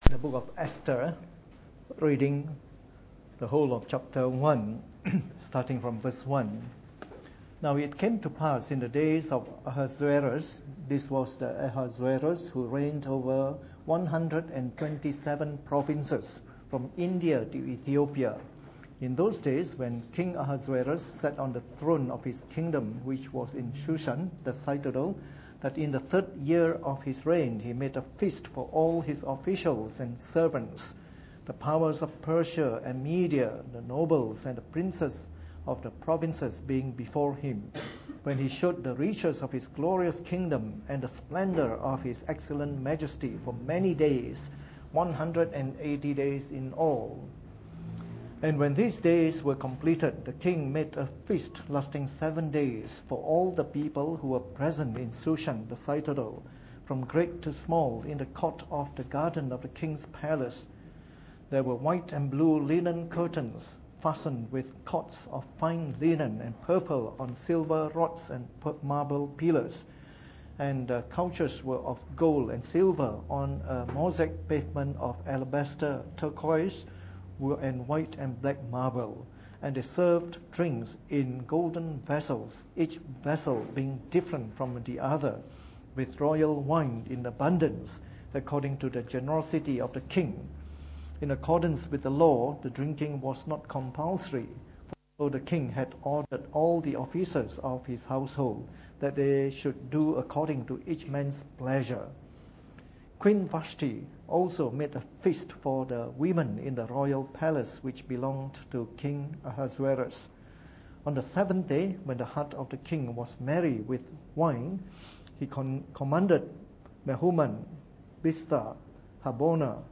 Preached on the 2nd of October 2013 during the Bible Study, the first in our series of talks on the Book of Esther.